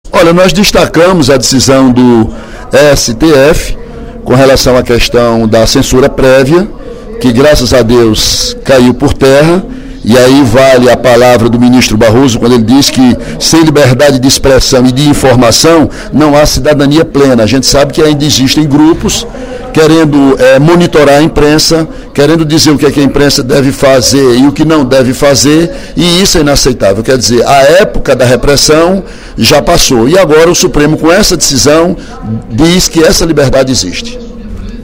O deputado Ely Aguiar (PSDC) avaliou, durante o primeiro expediente da sessão plenária desta quarta-feira (24/06), a decisão do Supremo Tribunal Federal (STF), que votou, na última semana, pelo fim da autorização prévia para a publicação de biografias.